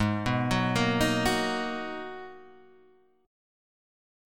G#m9 chord